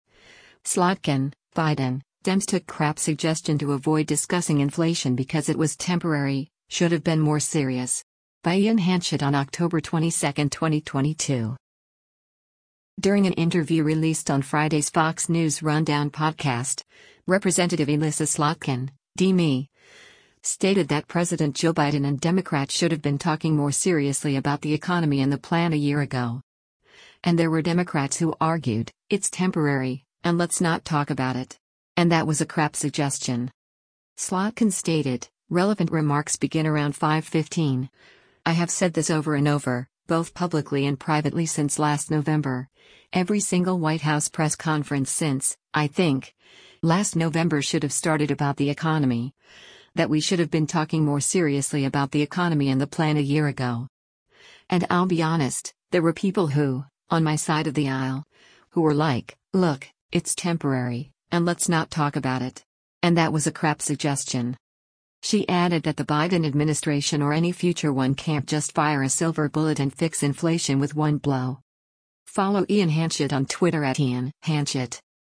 During an interview released on Friday’s “Fox News Rundown” podcast, Rep. Elissa Slotkin (D-MI) stated that President Joe Biden and Democrats “should have been talking more seriously about the economy and the plan a year ago.”